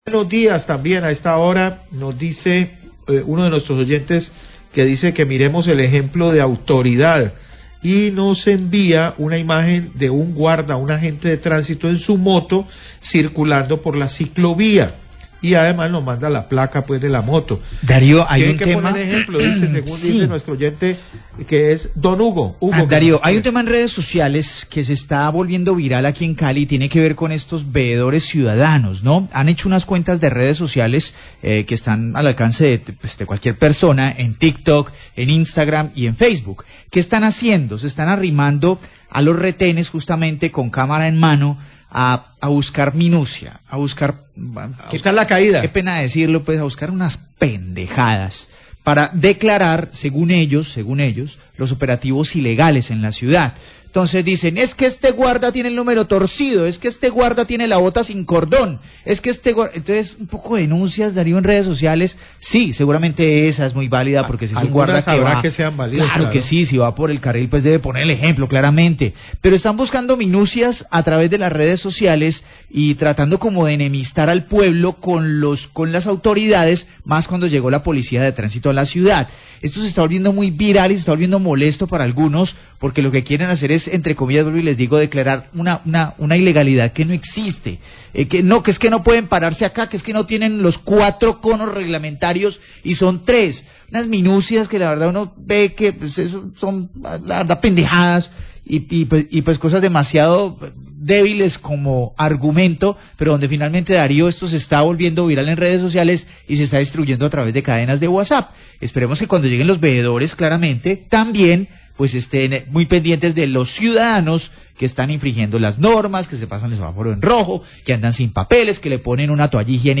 Periodistas comentan sobre veedurias a operativos de Sria de Movilidad que se viralizan en redes sociales
Radio
Periodistas del noticiero comentan que hay una situación con los veedores ciudadanos sobre los videos que publican en redes sociales intentando visibilizar deficiencias en los operativos de control de la Secretaria de Movilidad de Cali. Critican los periodistas que estos veedores se centran en minucias para desprestigiar los operativos.